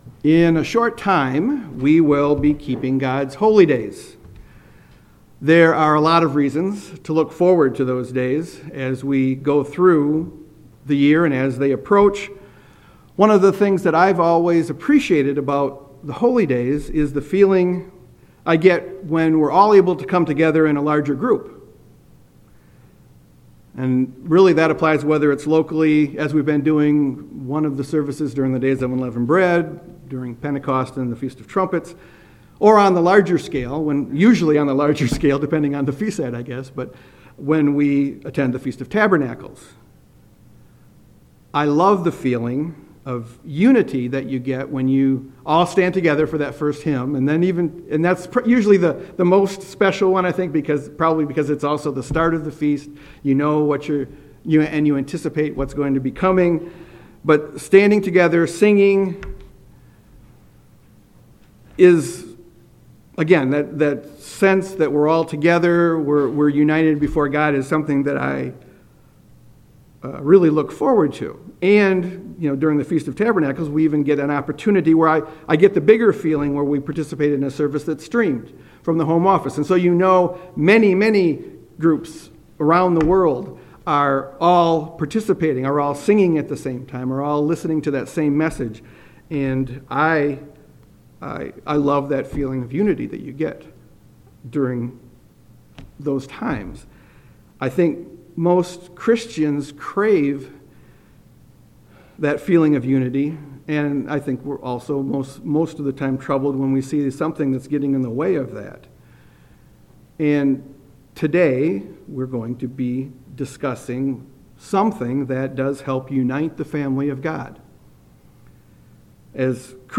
Sermons
Given in Grand Rapids, MI